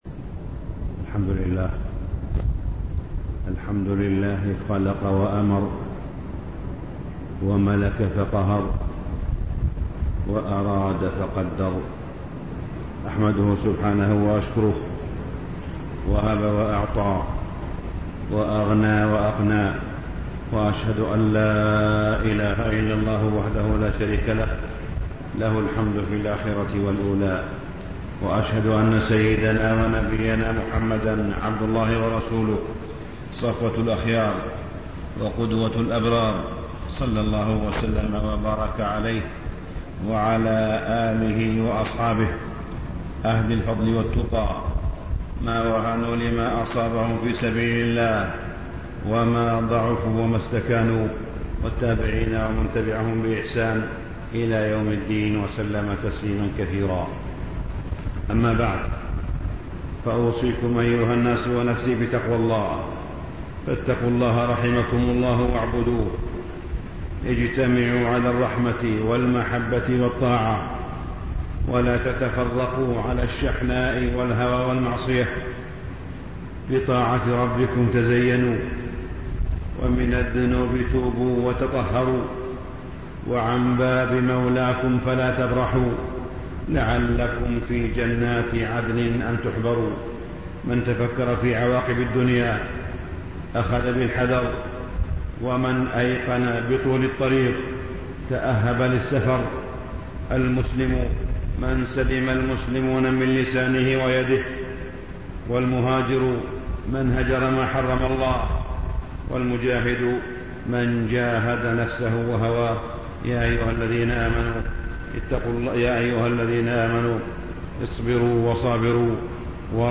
تاريخ النشر ٢٣ شوال ١٤٣٤ هـ المكان: المسجد الحرام الشيخ: معالي الشيخ أ.د. صالح بن عبدالله بن حميد معالي الشيخ أ.د. صالح بن عبدالله بن حميد حقوق الجار في الإسلام